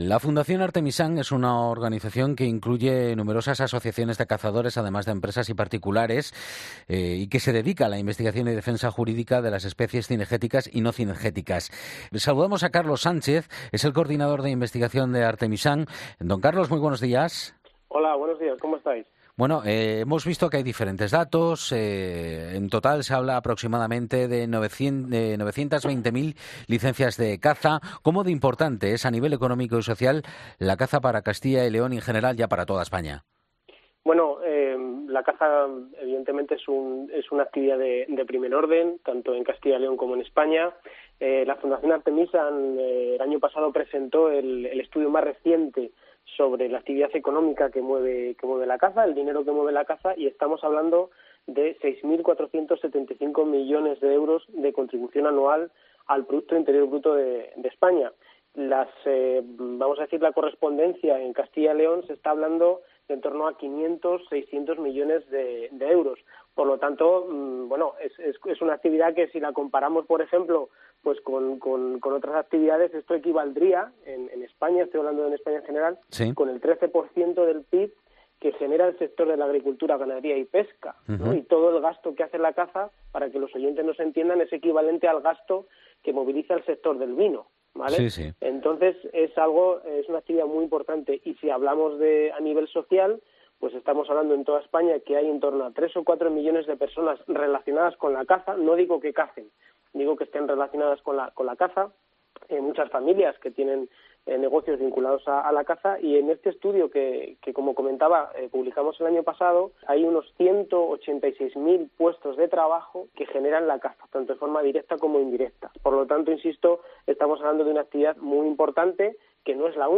Un investigador cinegético alerta de la importancia de esta actividad en nuestro país